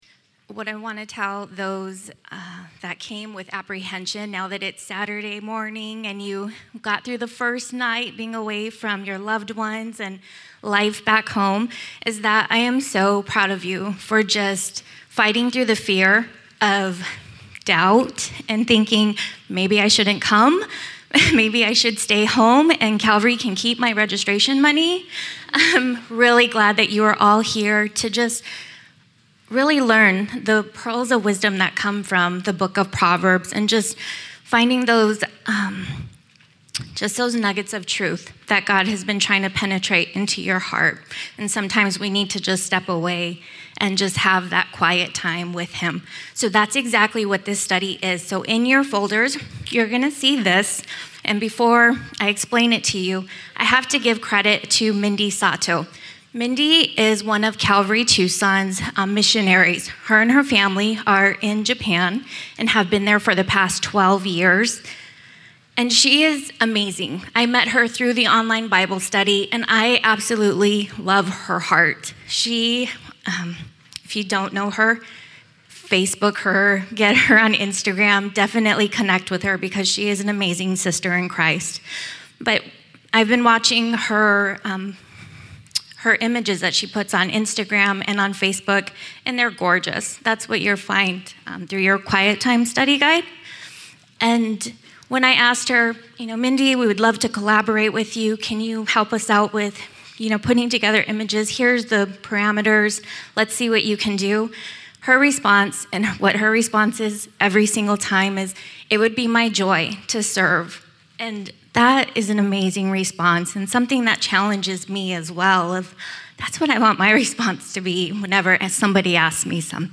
at the 2015 Women's Retreat: Pearl's of Wisdom